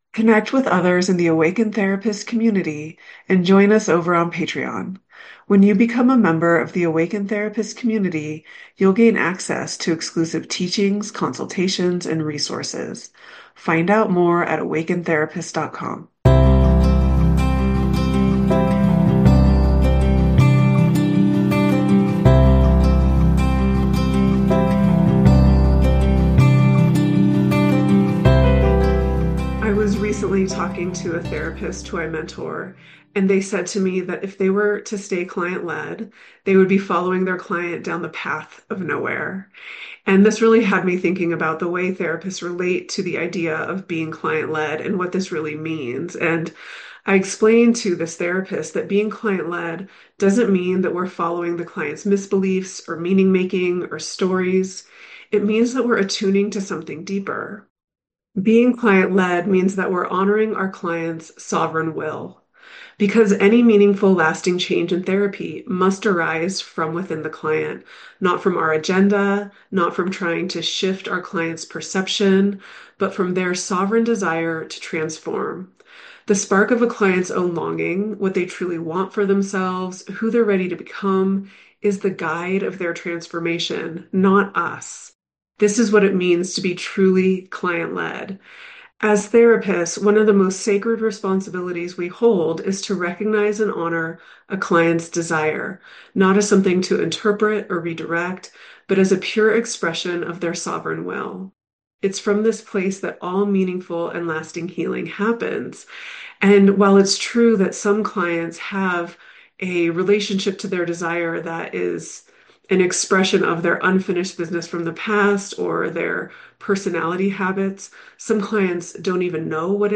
What does it actually mean to be client-led? And how do we ensure we’re not subtly leading our clients away from themselves—even when we have good intentions? In this solo teaching episode